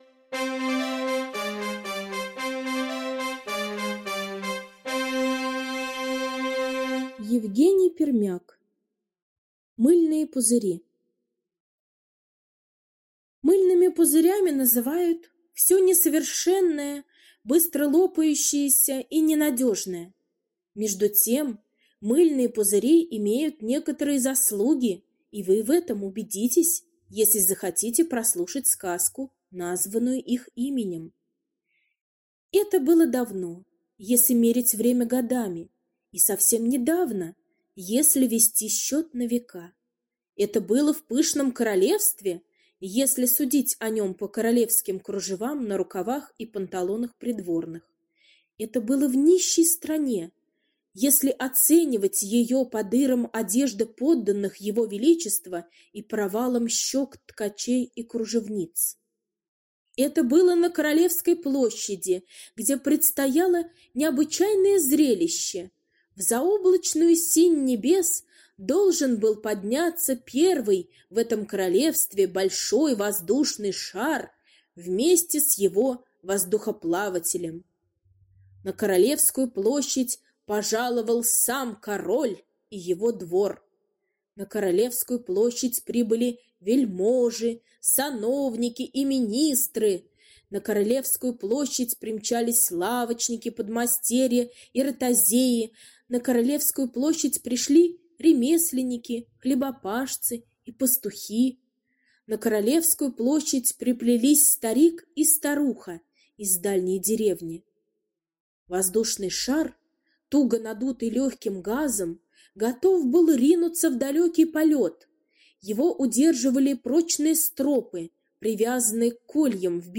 Мыльные пузыри — аудиосказка Пермяка Е. История о том, как давным-давно в одном королевстве должен был подняться первый в этом королевстве ...